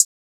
Closed Hats
SB6 Hihat (5).wav